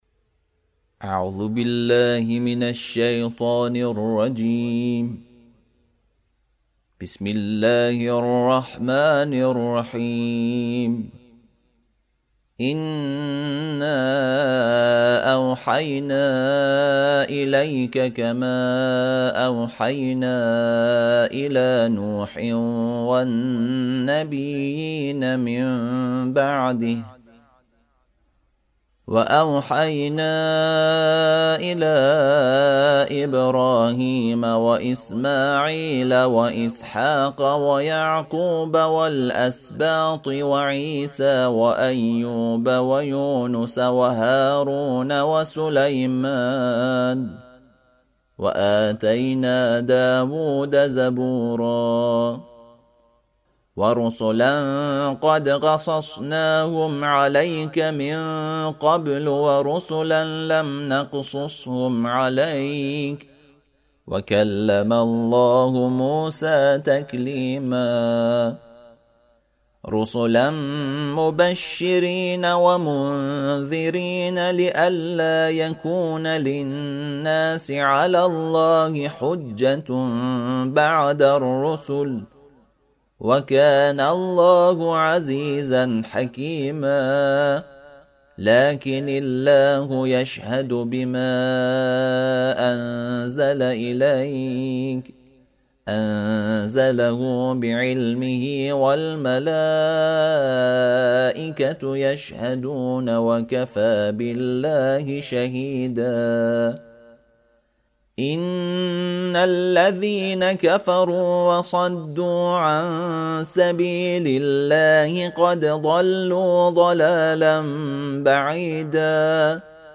ترتیل صفحه ۱۰۴ سوره مبارکه نساء(جزء ششم)